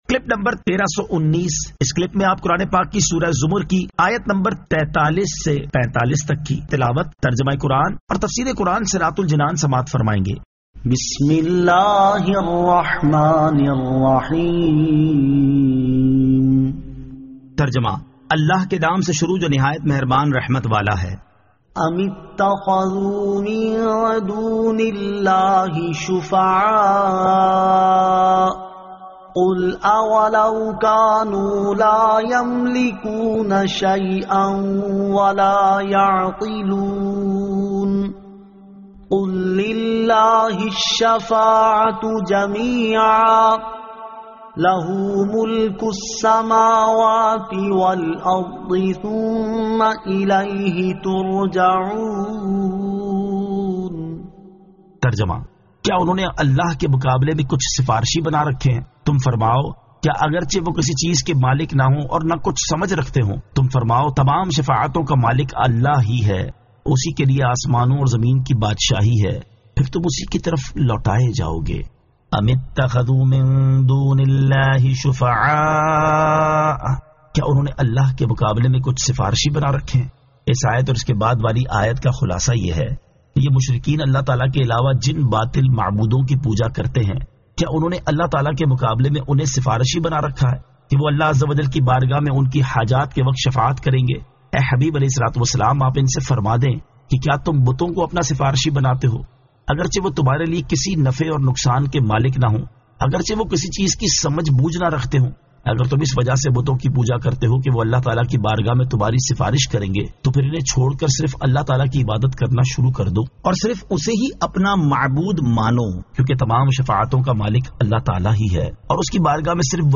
Surah Az-Zamar 43 To 45 Tilawat , Tarjama , Tafseer